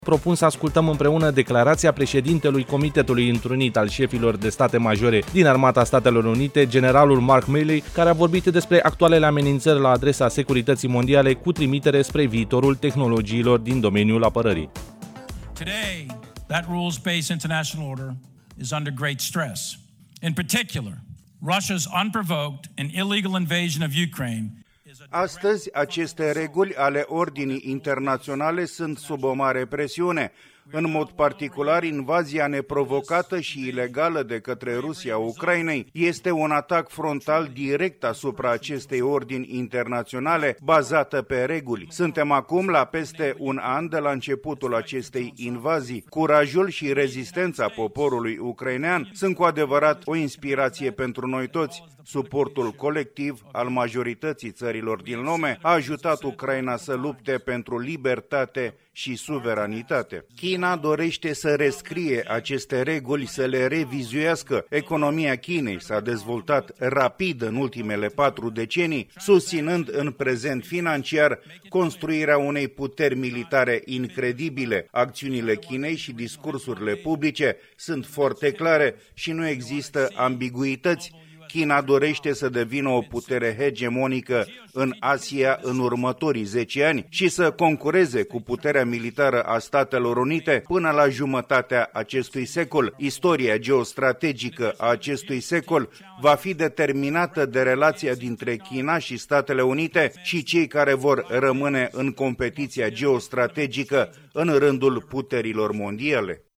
S1-Declaratie-general-Milley.mp3